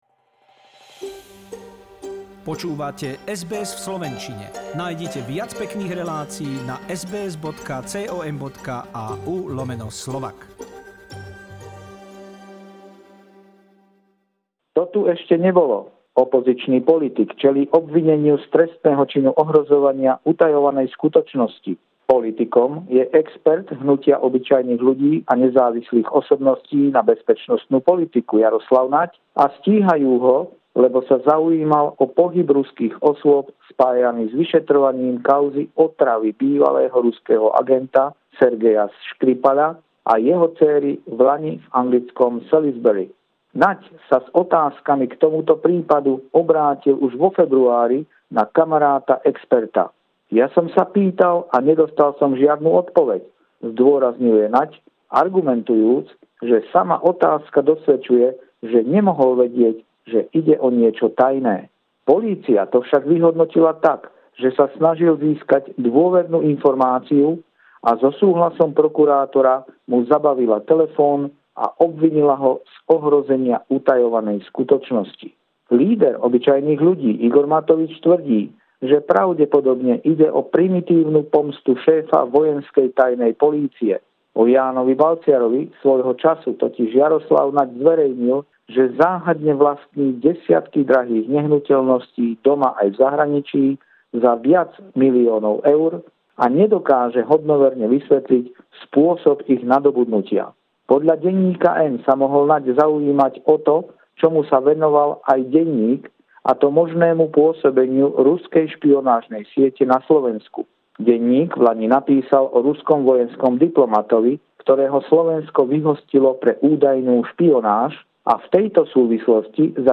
Regular stringer report